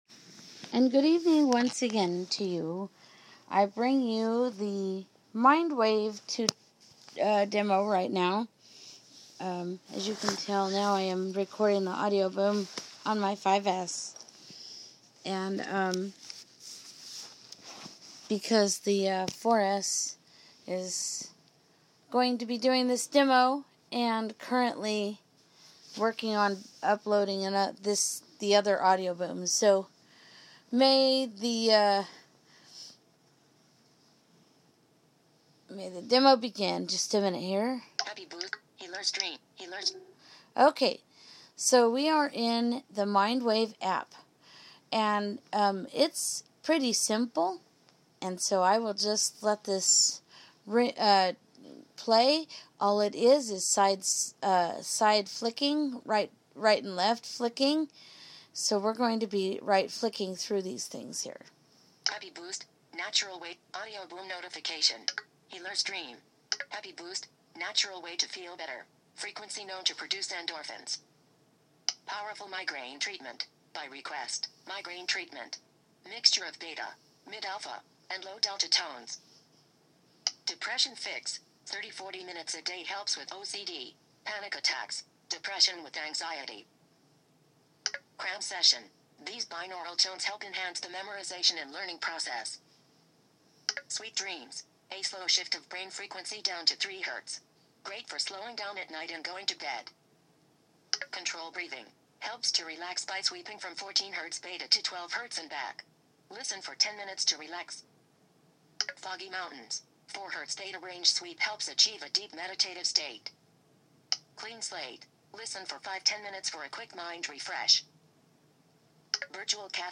This is a Nother app doing binaural beats. This one is very simple and does not have as many options.